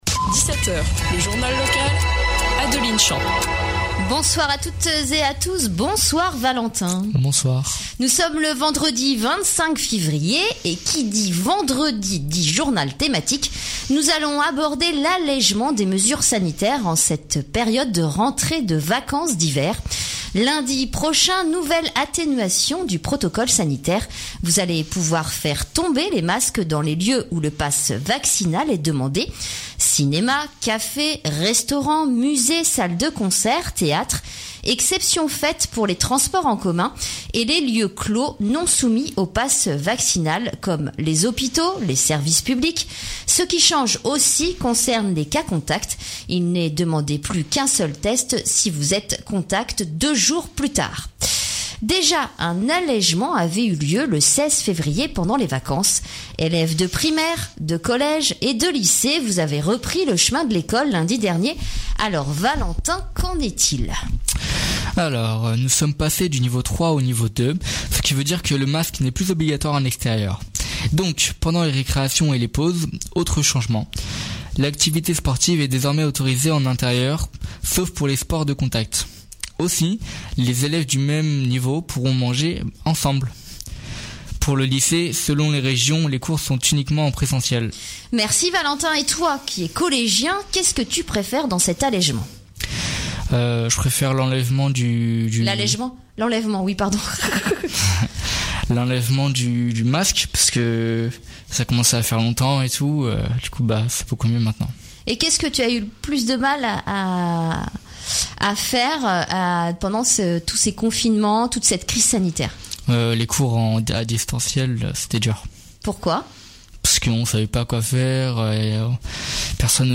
Le journal local du 25 février 2022.
Le vendredi, la Tribu te propose un journal thématique. Cette semaine, nous parlons de l’allègement du protocole sanitaire à l’école mais aussi dans les salles de concerts !